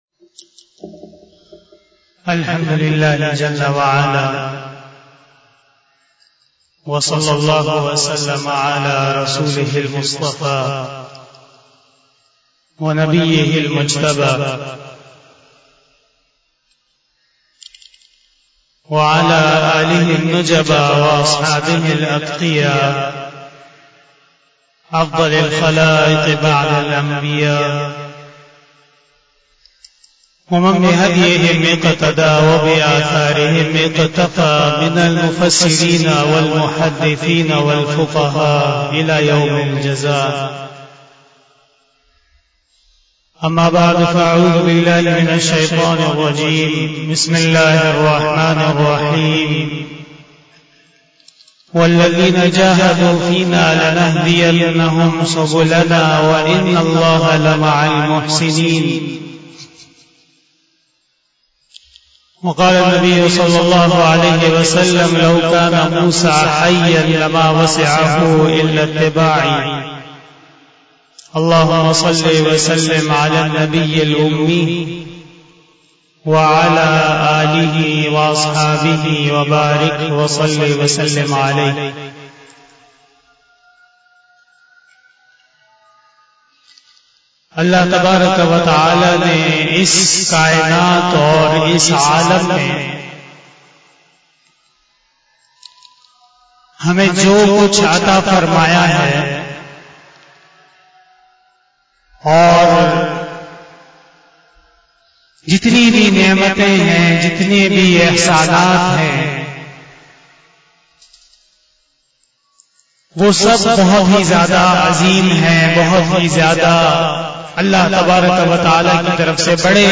35 BAYAN E JUMA TUL MUBARAK 27 August 2021 (18 Muharram 1443H)